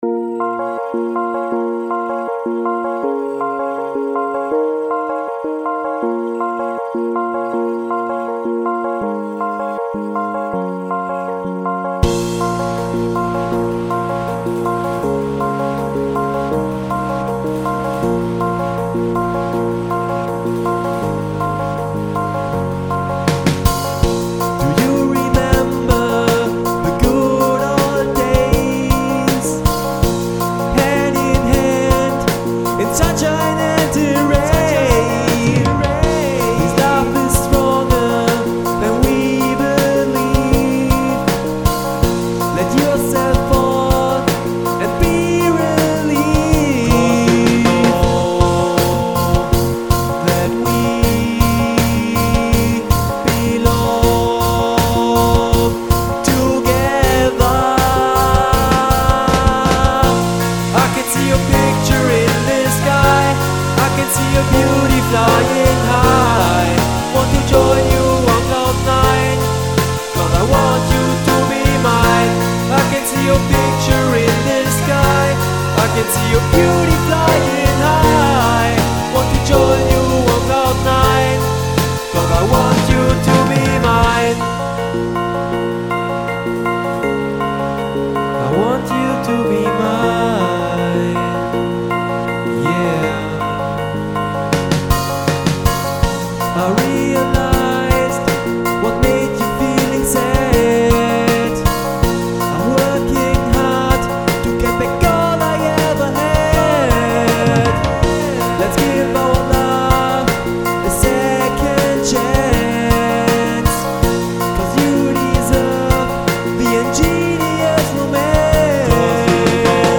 voc
keys